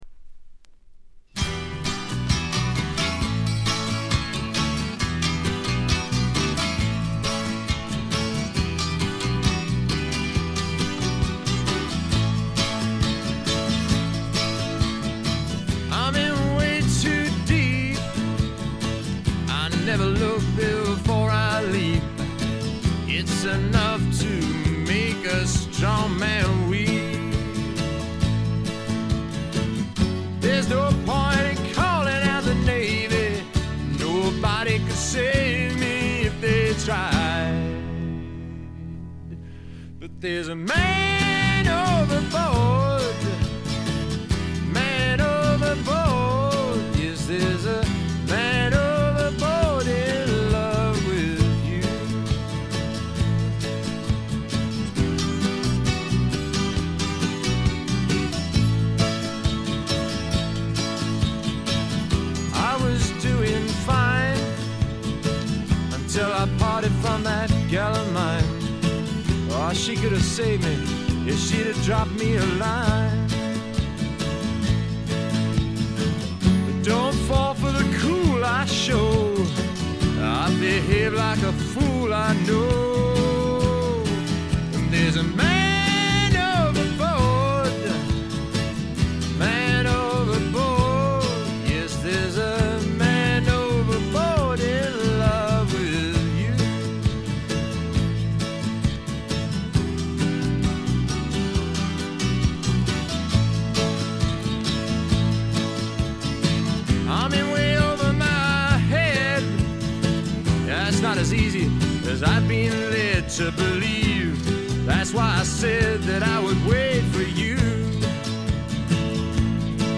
静音部でのバックグラウンドノイズ。チリプチ少々。
胸に染みる弾き語り、アコースティックなフォークロック、とにかく全編が味わい深い名曲揃いで完成度はものすごく高いです。
試聴曲は現品からの取り込み音源です。